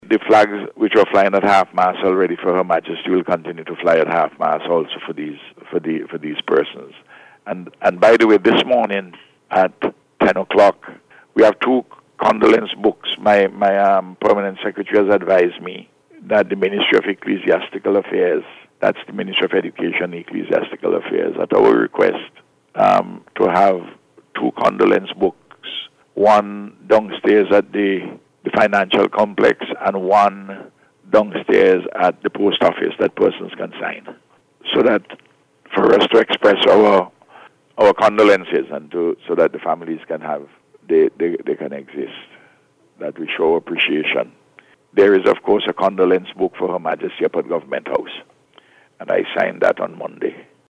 Word of this came from Prime Minister Dr. Ralph Gonsalves, during NBC’s Face to Face programme yesterday.